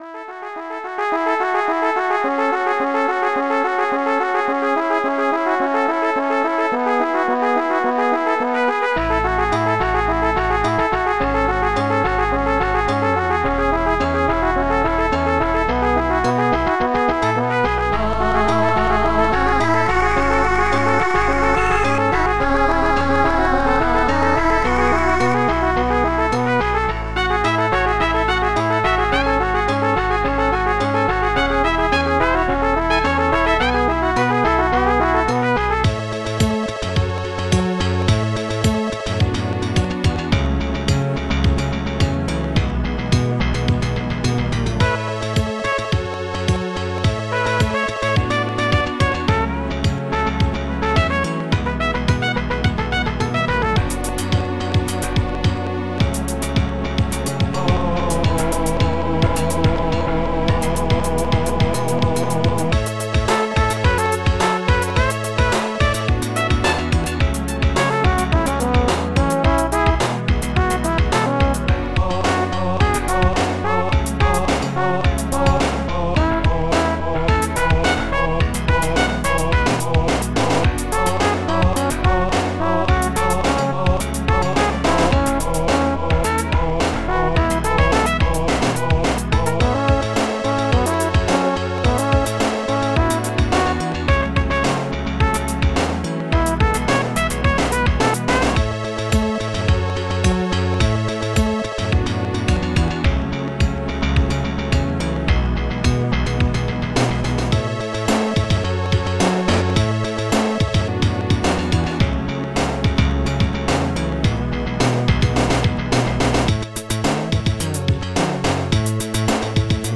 ST-16:440gated-snar
ST-18:Bassgittar
ST-18:pianoI
ST-18:Trumpet
ST-15:hihat-3
ST-17:bassdrum.f
ST-06:stringsmaj
ST-12:aachoir
ST-05:flute